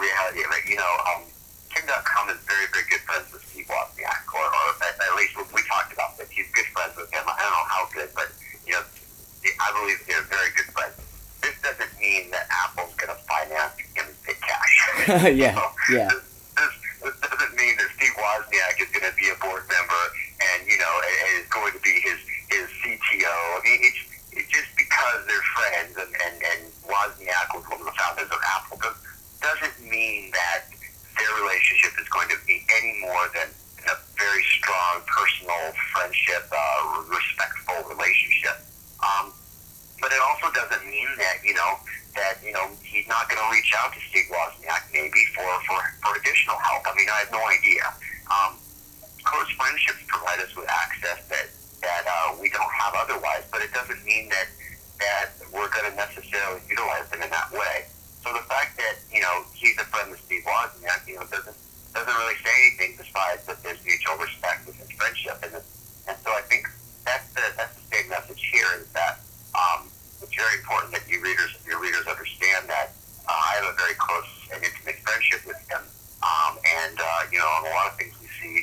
Recorded on 8/8/2016 via telephone conversation so please forgive the poor quality of the recording.